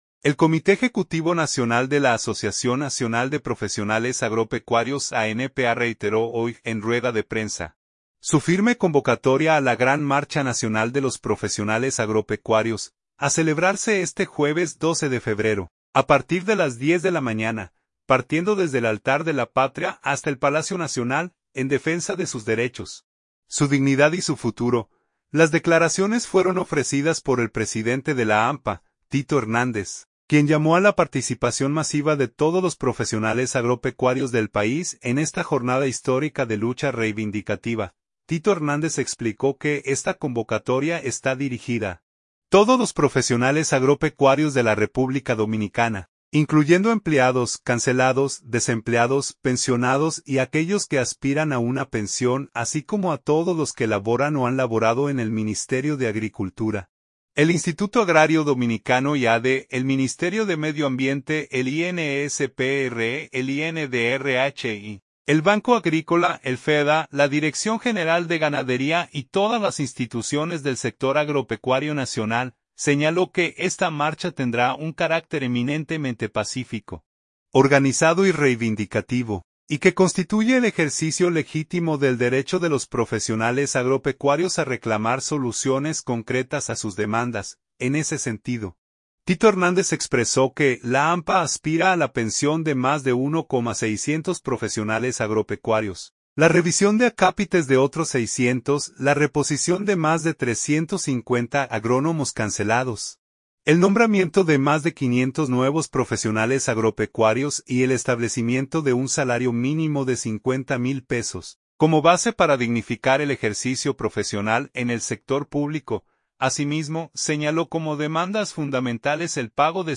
El Comité Ejecutivo Nacional de la Asociación Nacional de Profesionales Agropecuarios (ANPA) reiteró hoy, en rueda de prensa, su firme convocatoria a la gran marcha nacional de los profesionales agropecuarios, a celebrarse este jueves 12 de febrero, a partir de las 10:00 de la mañana, partiendo desde el Altar de la Patria hasta el Palacio Nacional, en defensa de sus derechos, su dignidad y su futuro.